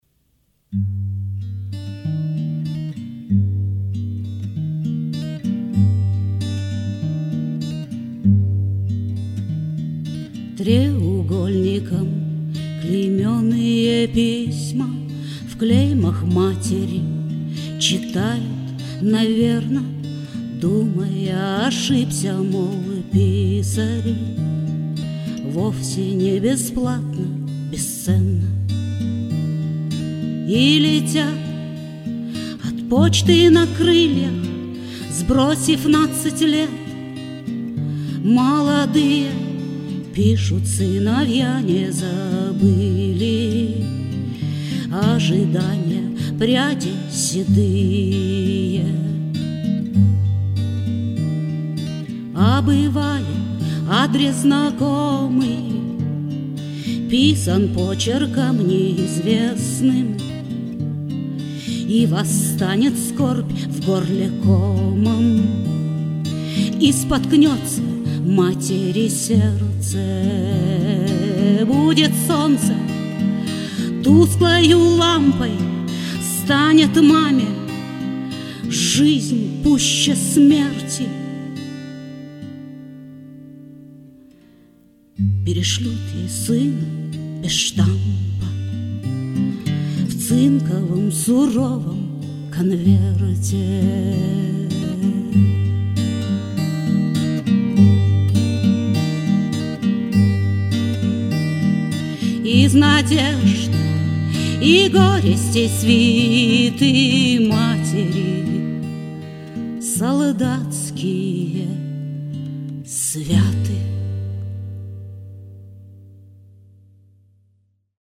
Многие записи, здесь представленные, неважного качества, записывалось всё, когда я только начинала осваивать компьютер) Со своих страничек в инете я их убрала давно, и очень приятно, что Вы разыскали эти старые песни)